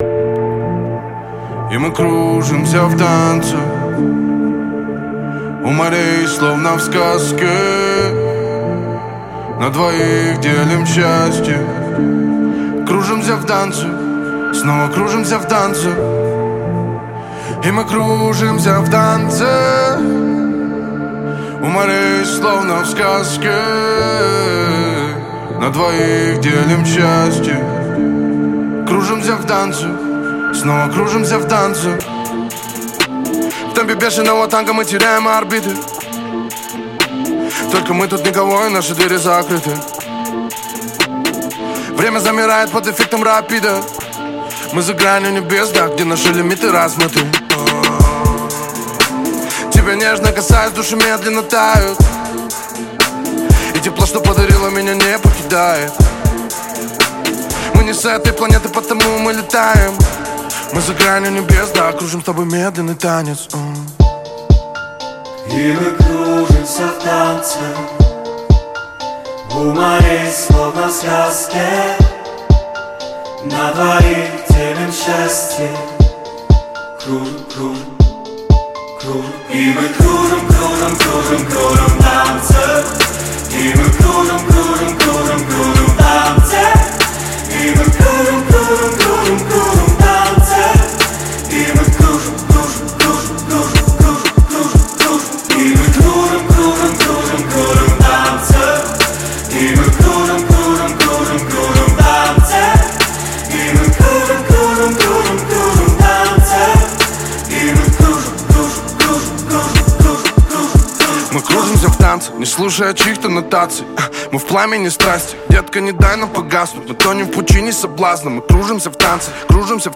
Жанр: Русский рэп / Хип-хоп
• Жанр песни: Русский рэп / Хип-хоп